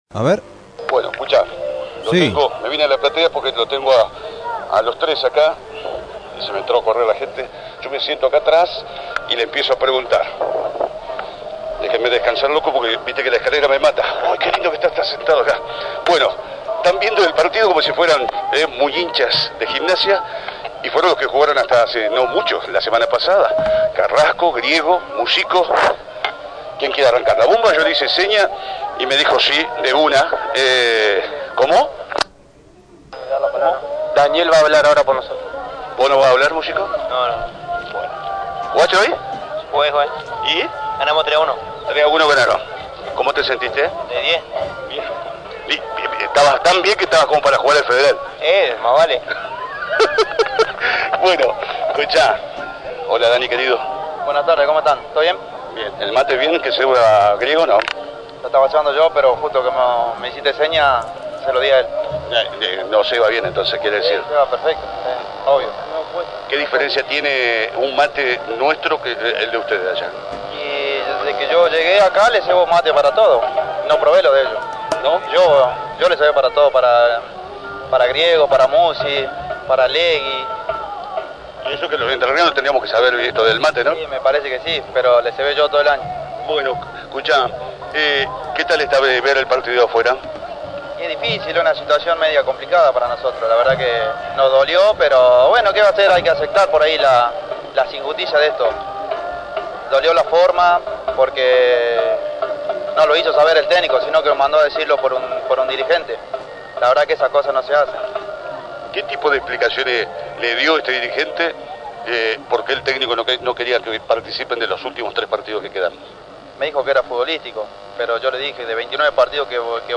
Audio del reportaje en FM Sensaciones. https